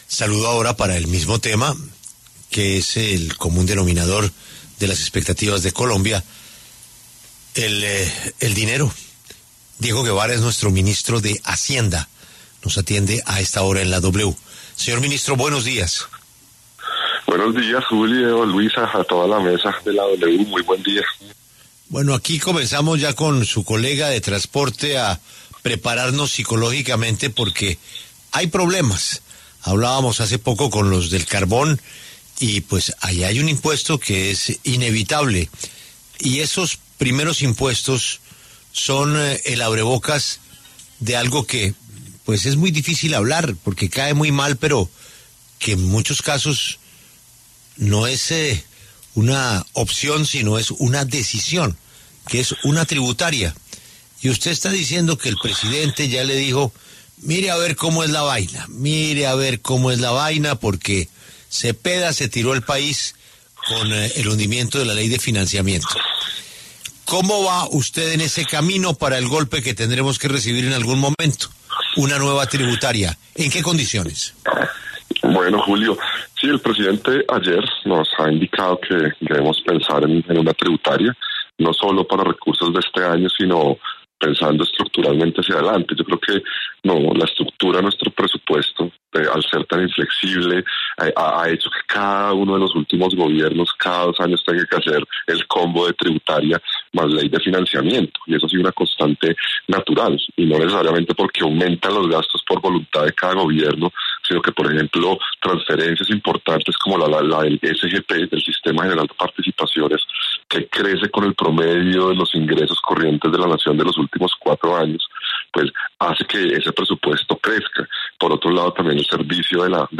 Diego Guevara, ministro de Hacienda, afirmó en W Radio que buscarán incluso recaudar más de 12 billones de pesos para la próxima reforma tributaria.